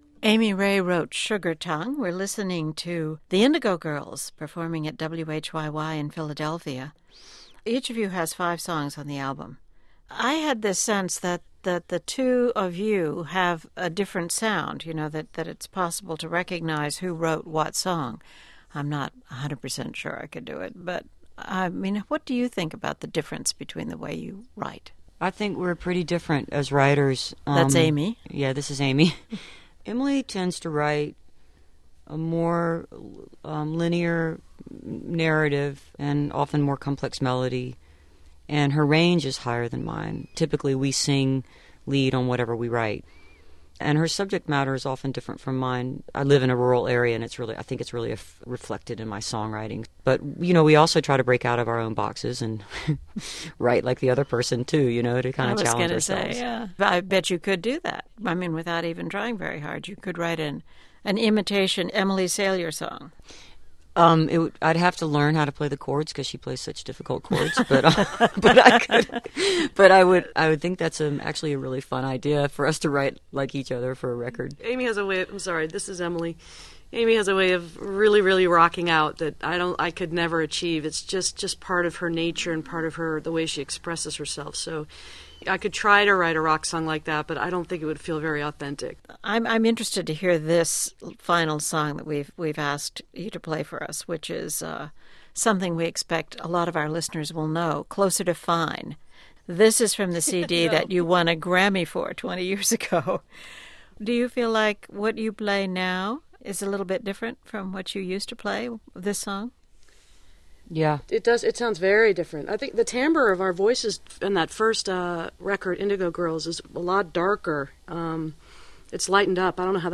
04. interview (1:40)